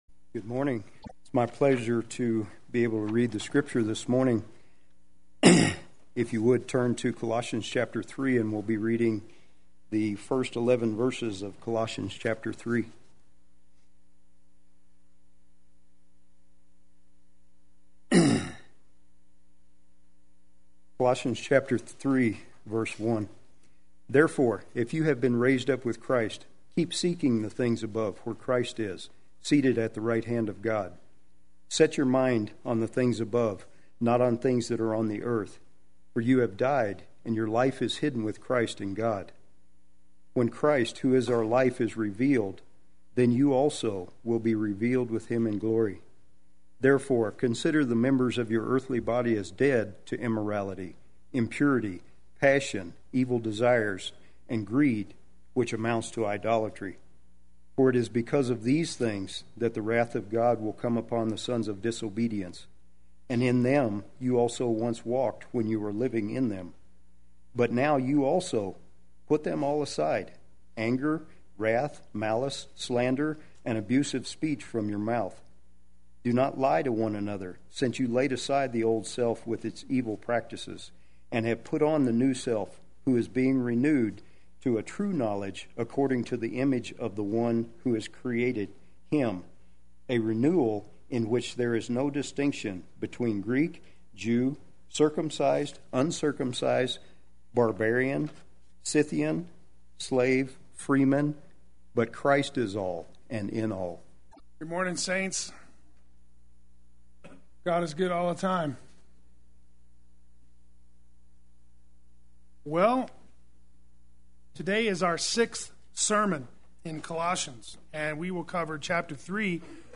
Play Sermon Get HCF Teaching Automatically.
Raised Up With Him Sunday Worship